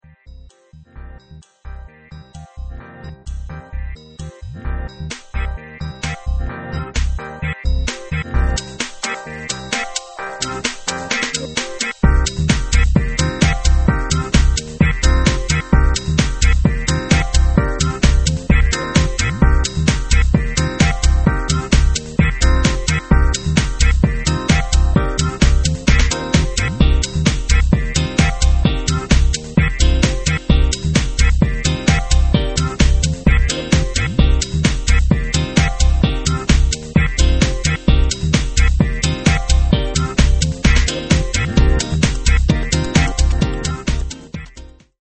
2005 nervous fast instr.